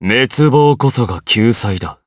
DarkKasel-Vox_Victory_jp.wav